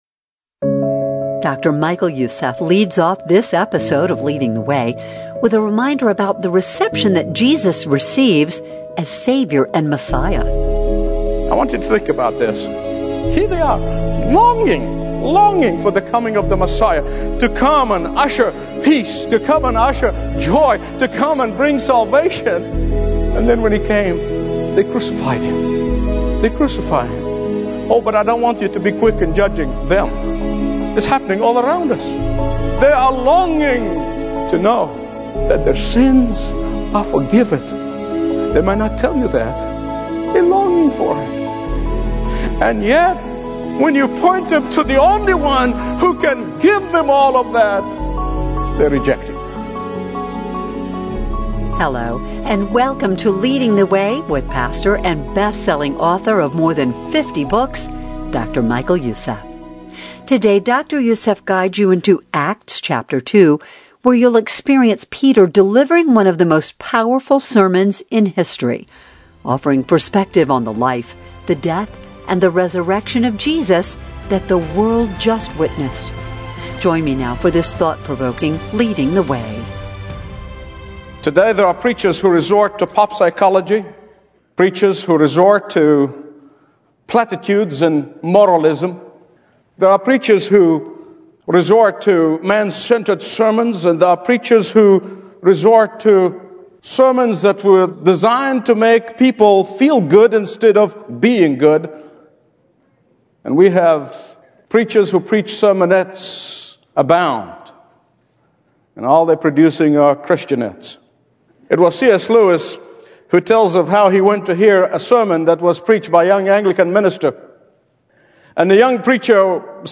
Listen to Powerful Sermon